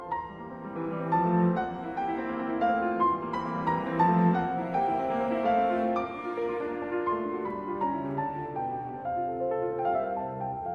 Orchestre : 1 flûte, 2 hautbois, 2 bassons, 2 cors en Ut, 2 trompettes en Ut, 2 timbales (Ut et Sol), quatuor.
Refrain (Mes. 115, env. 2'46'')
Enoncé par le piano suivi du Tutti pour nous mener, après trois accords vigoureux de l'orchestre, au 2e Couplet qui commence le Développement dans la tonalité inattendue ici de la mineur.